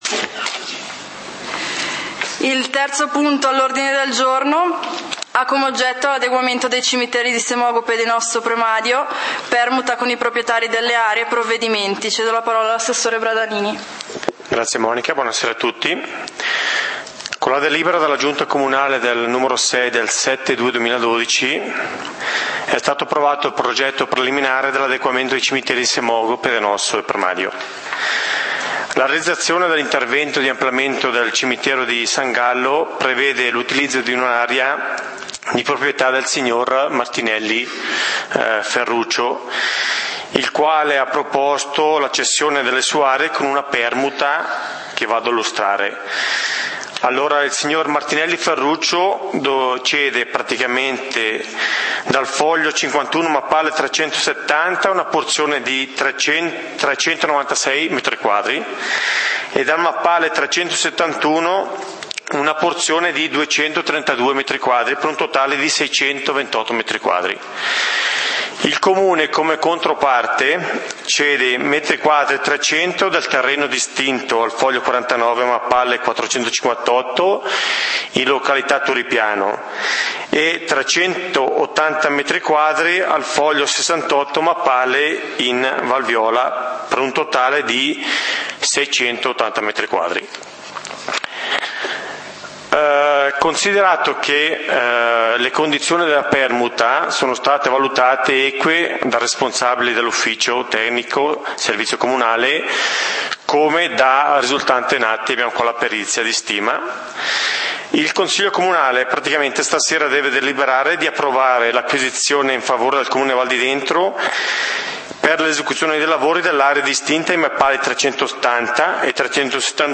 Punti del consiglio comunale di Valdidentro del 30 Ottobre 2012